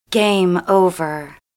女生说gameover音效_人物音效音效配乐_免费素材下载_提案神器
女生说gameover音效免费音频素材下载